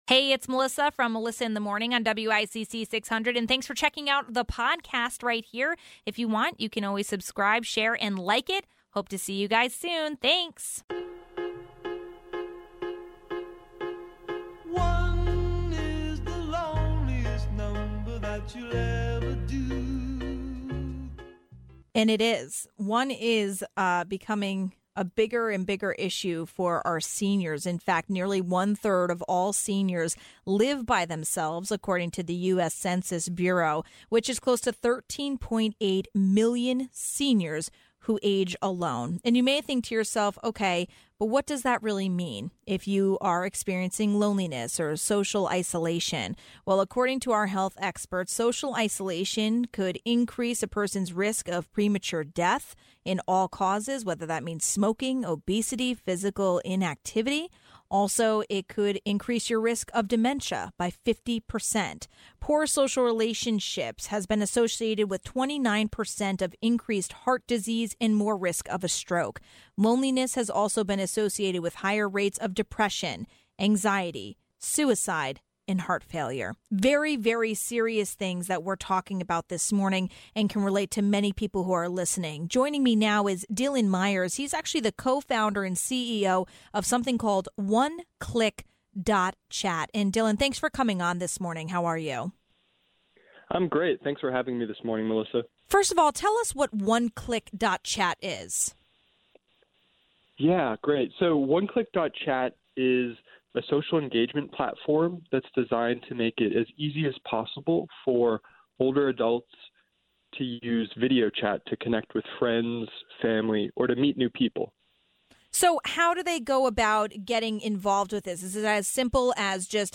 ((00:11)) 2. Bridgeport Mayor Joe Ganim talks about the future of UB, covid testing and illegal guns off the streets.